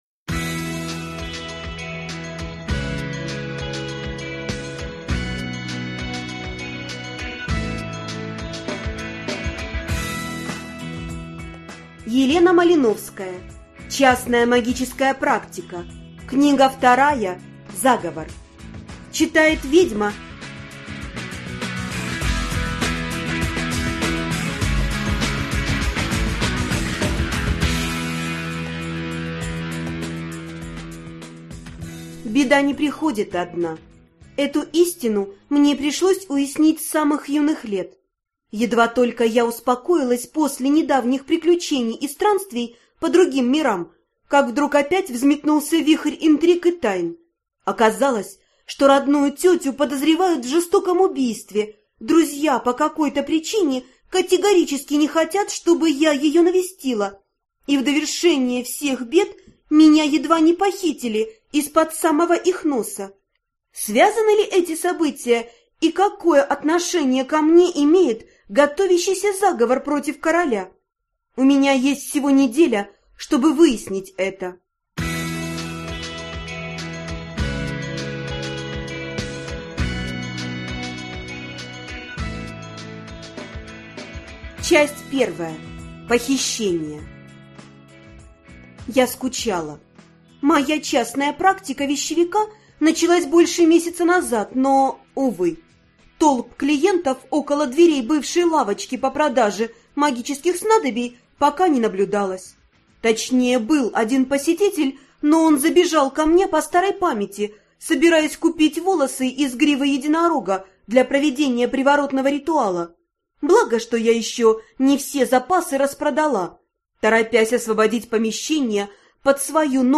Аудиокнига Заговор | Библиотека аудиокниг